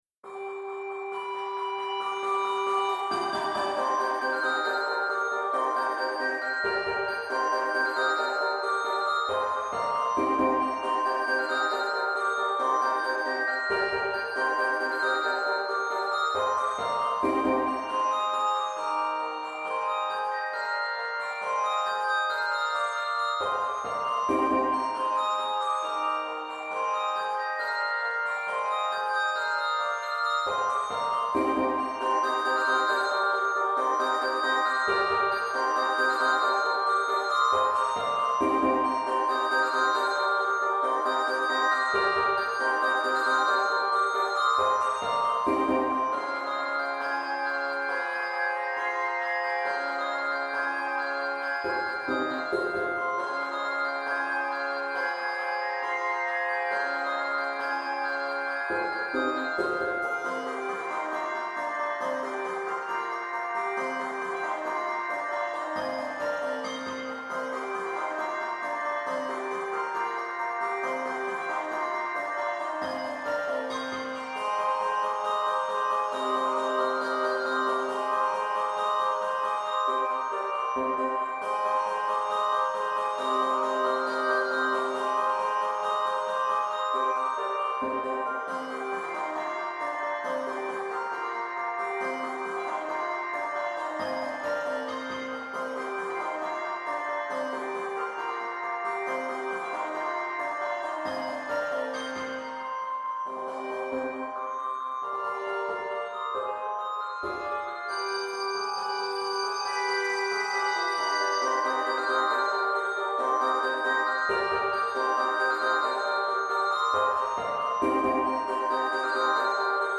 Voicing: Handbells 2-3 Octave